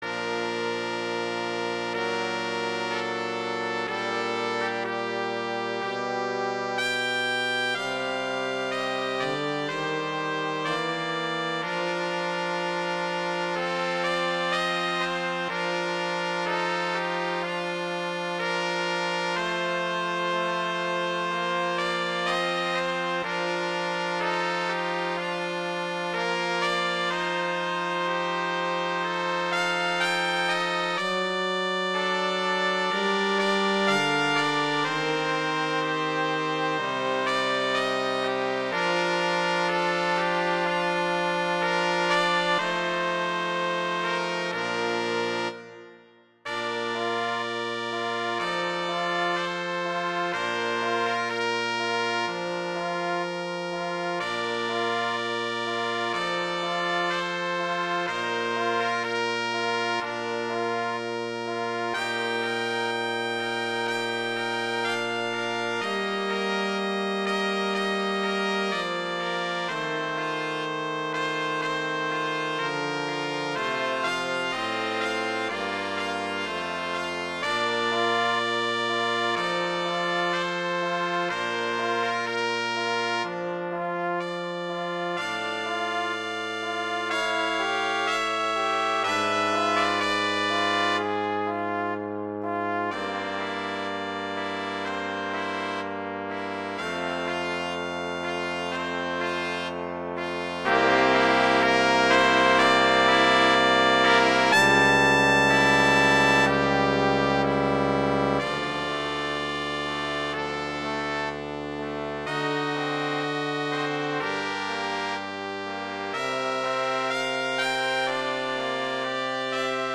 transcription for brass quartet
Bb major
♩=62 BPM (real metronome 63 BPM)
trumpet I:
trumpet II:
trombone:
tuba: